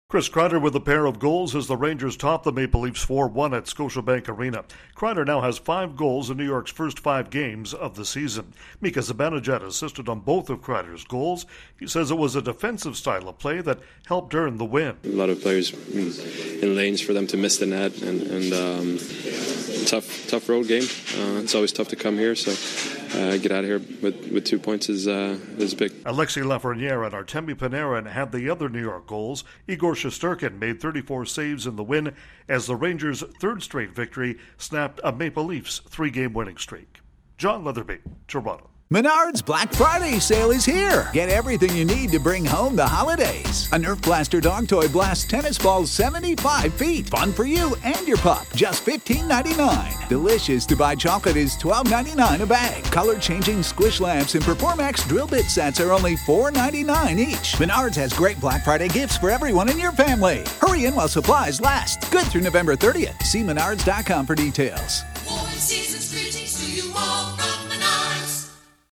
The Rangers run their season-opening point streak to five games at 4-0-1. Correspondent